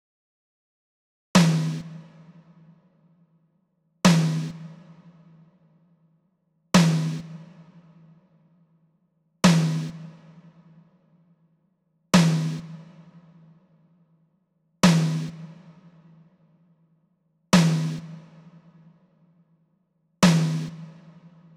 Drum Tracks
03-dt tom snare A.wav